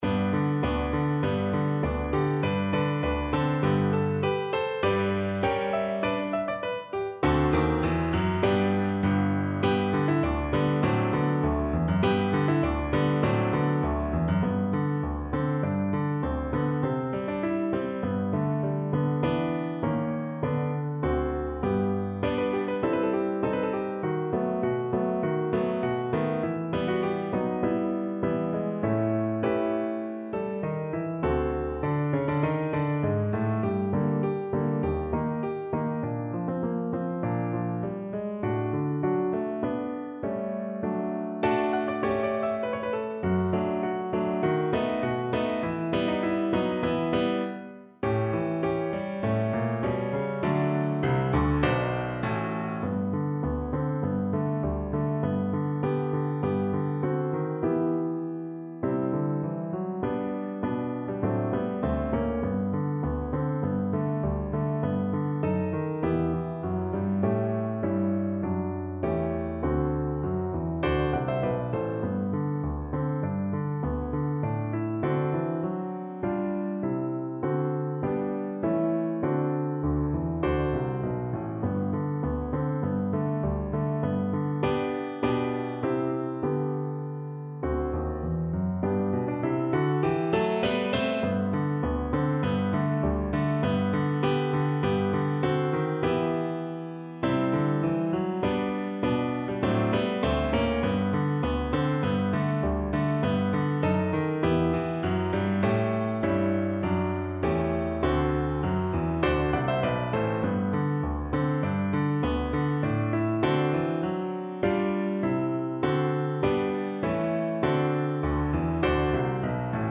2/4 (View more 2/4 Music)
Tempo di Marcia
Pop (View more Pop Viola Music)